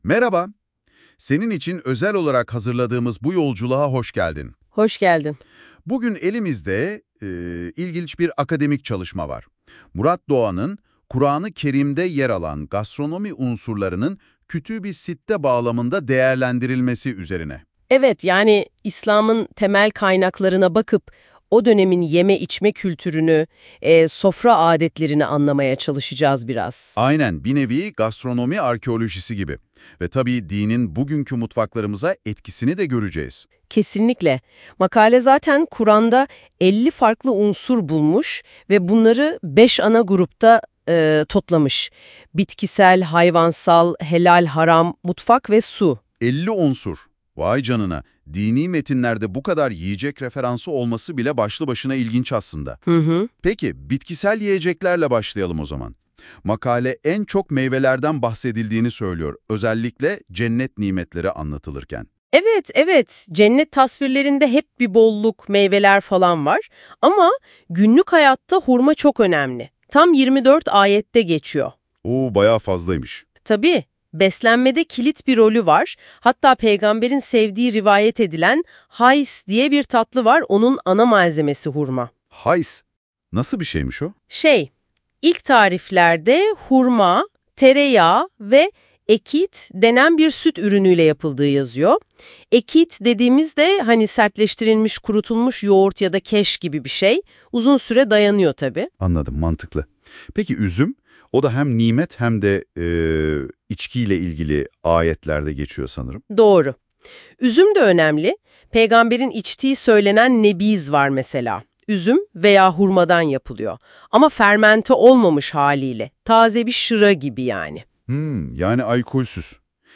Gastronomi unsurları, Söyleşi